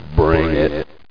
echo.mp3